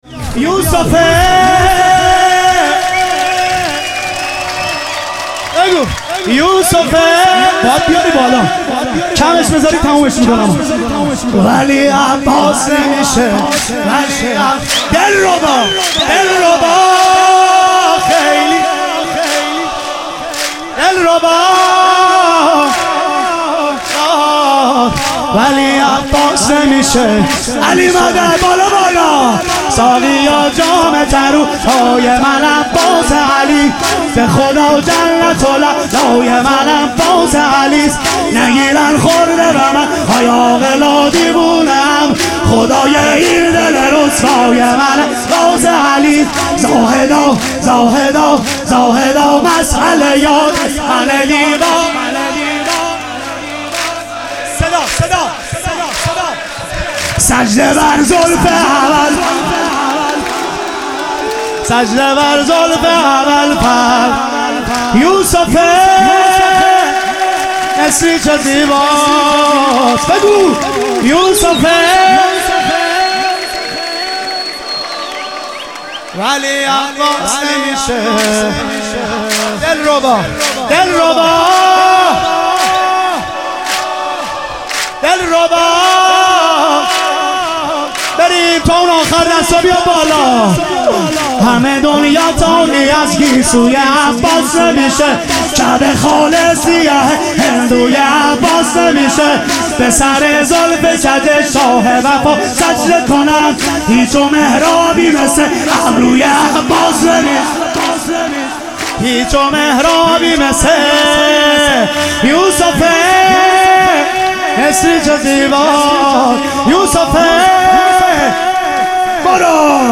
ظهور وجود مقدس حضرت عباس علیه السلام - تک